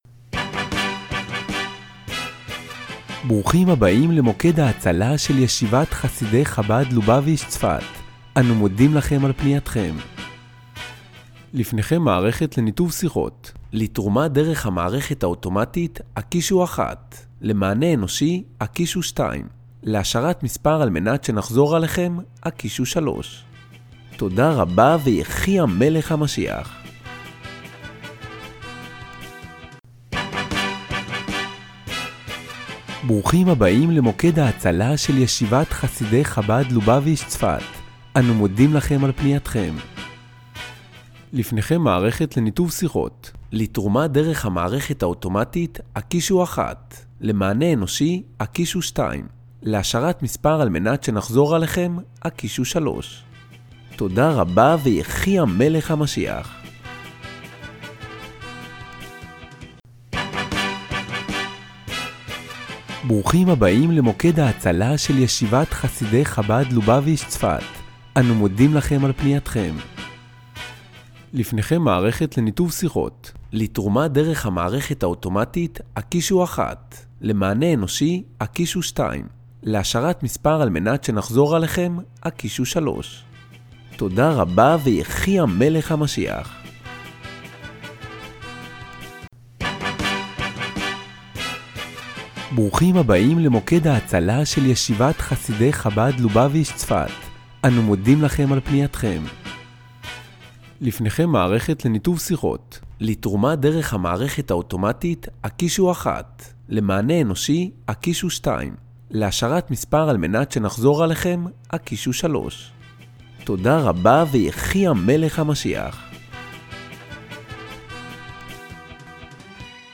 משתף כאן כמה דוגמאות קריינות של פתיח טלפוני.